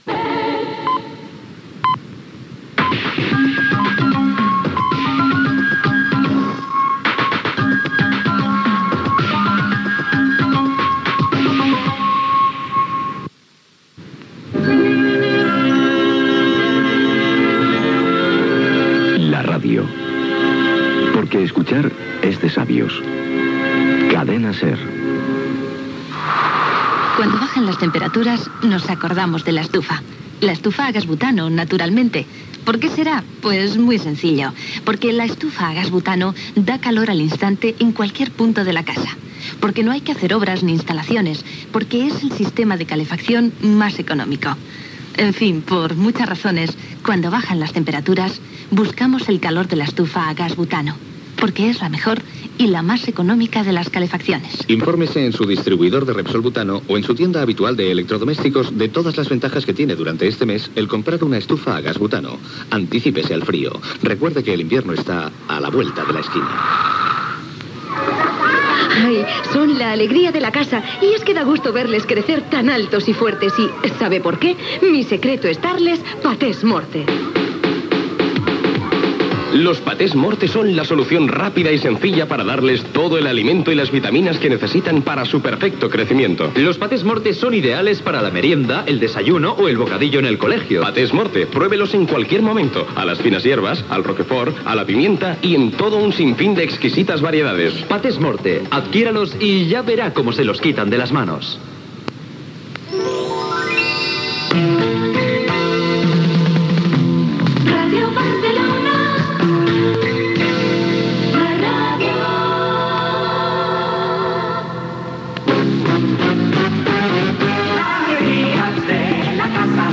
Sintonia de la SER, identificació, publicitat, indicatiu de l'emissora, careta del programa, hora, presentació, equip, sumari
Entreteniment